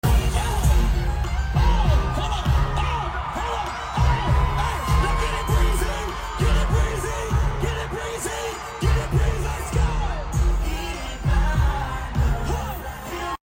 concert